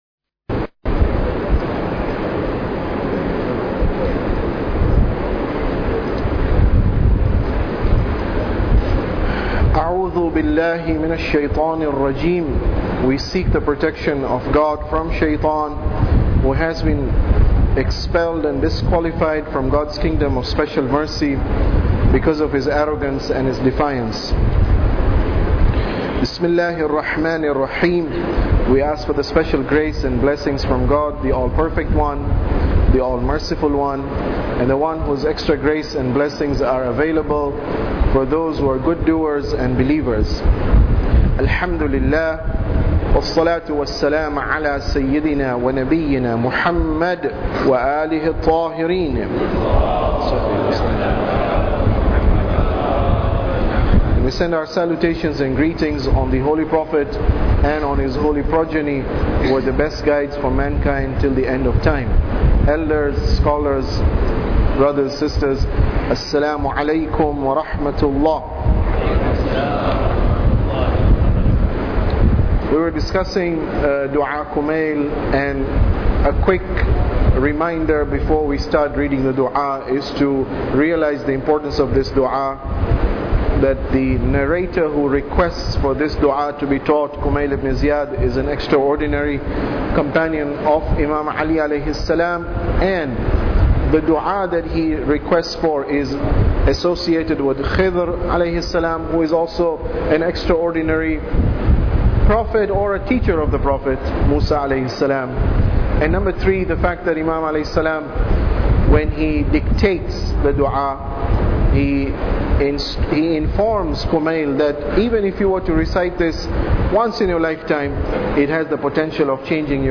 Tafsir Dua Kumail Lecture 24